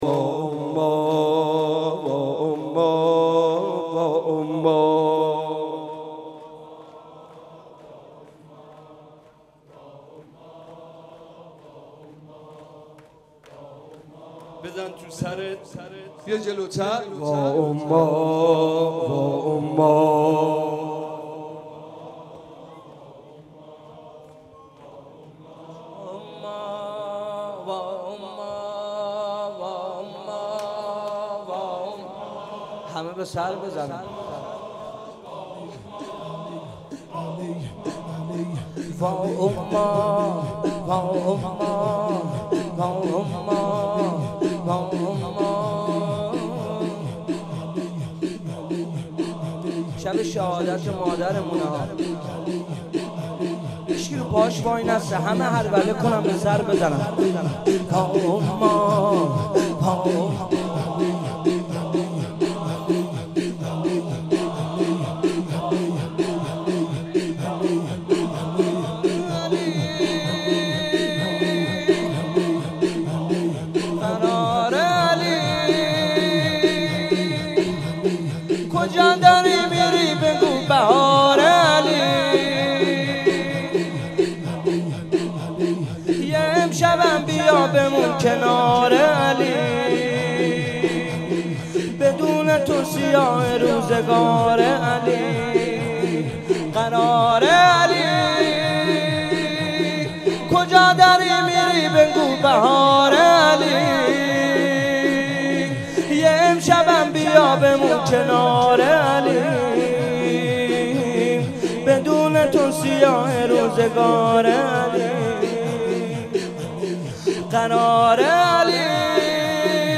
شب شهادت حضرت زهرا(س) - هیئت ثارالله(مسجد امام الهادی) - اسفند93
صوت مداحی